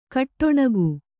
♪ kaṭṭoṇagu